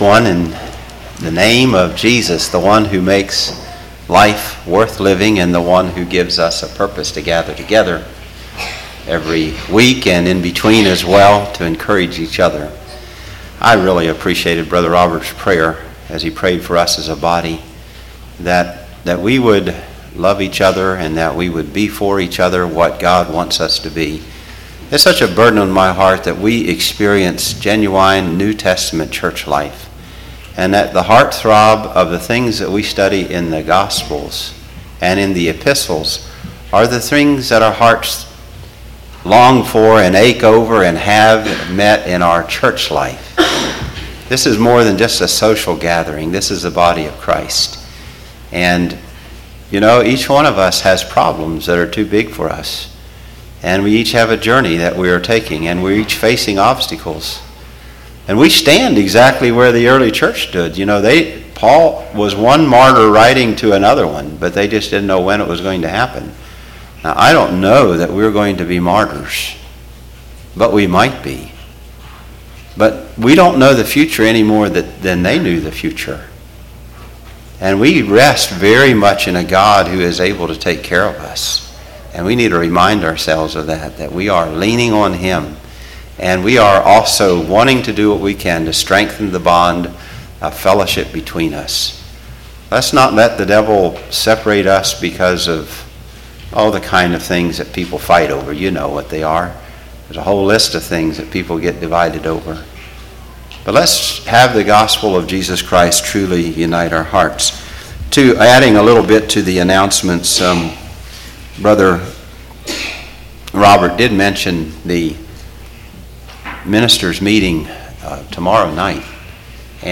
Congregation: Susquehanna Valley
Sermon